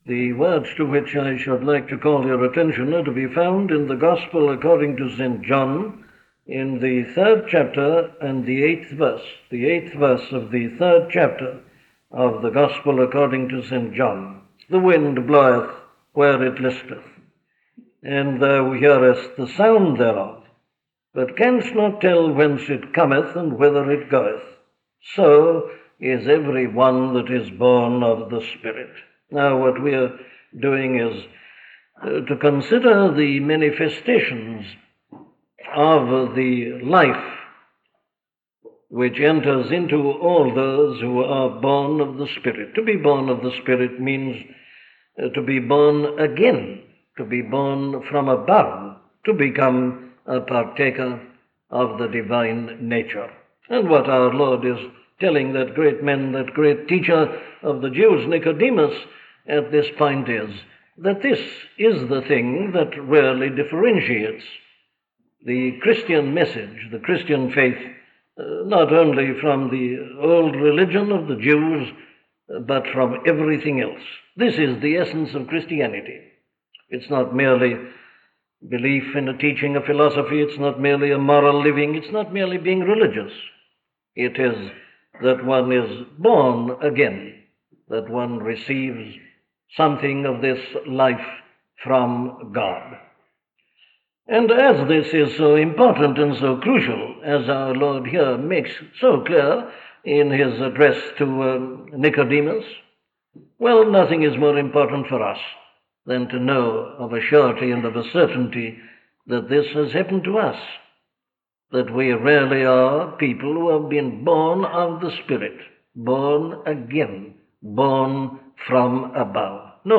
O God, Thou Art My God, Part 2 - a sermon from Dr. Martyn Lloyd Jones